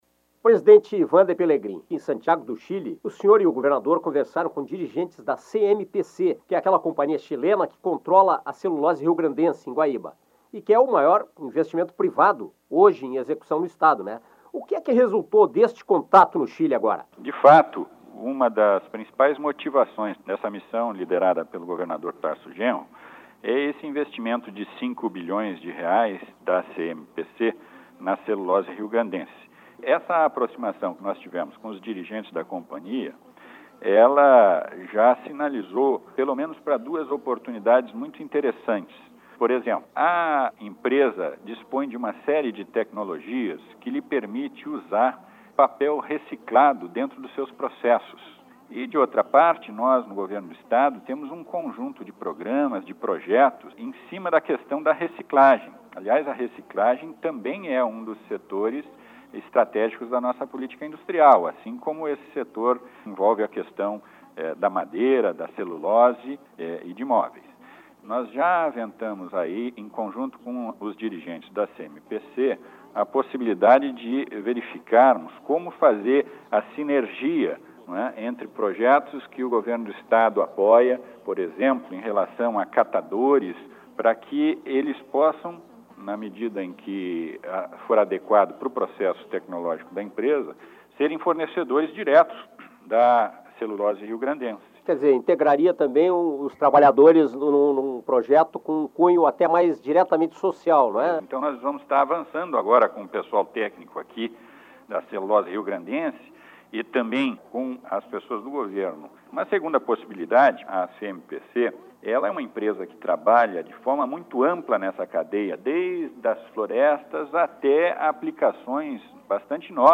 ENTREVISTA - Presidente da AGDI garante interesse de chilenos em ampliar investimentos no RS